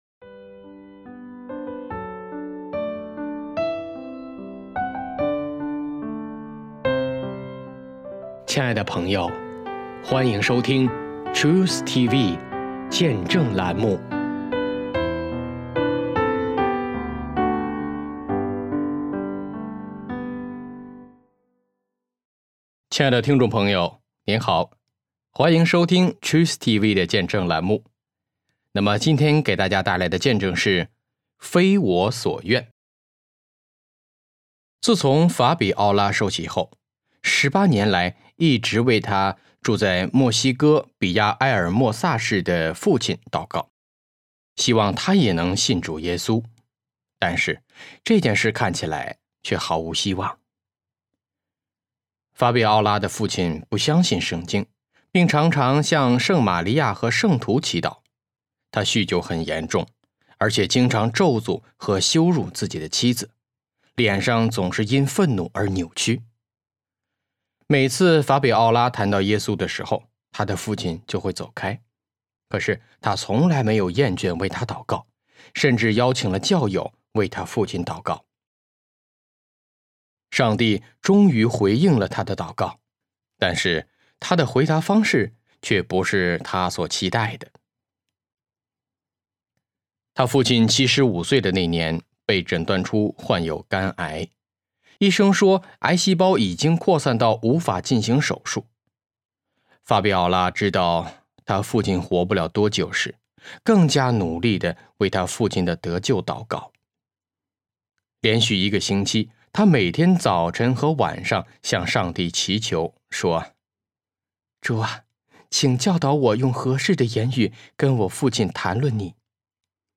翻译/朗读 : TruthTV 小组